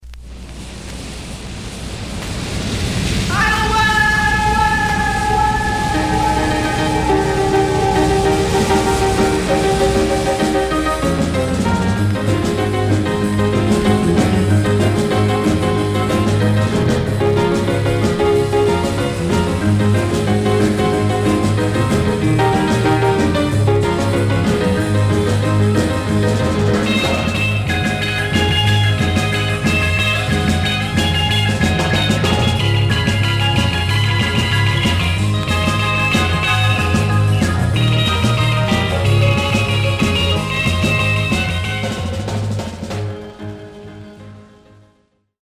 Surface noise/wear
Mono
R & R Instrumental